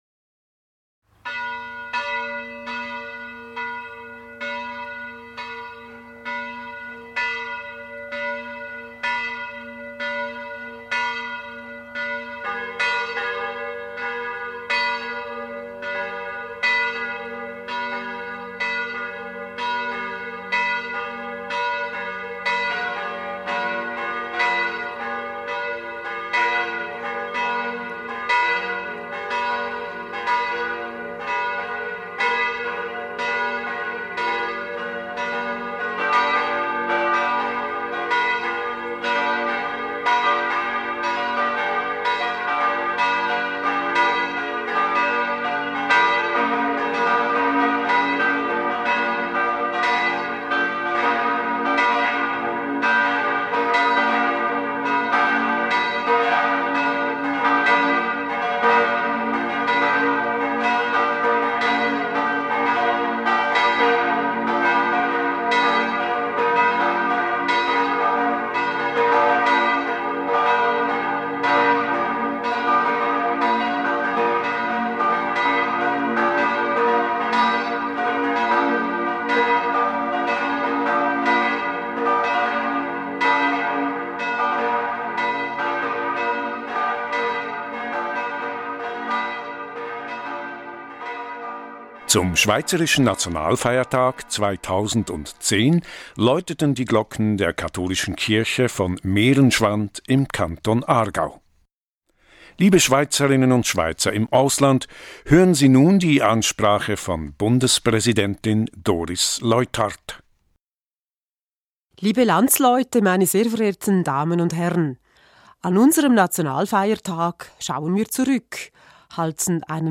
Die Rede von Bundespräsidentin Doris Leuthard zum Schweizer Nationalfeiertag an die Schweizerinnen und Schweizer im Ausland.